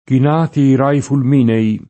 kin#ti i r#i fulm&ne-i] (Manzoni) — cfr. raggiare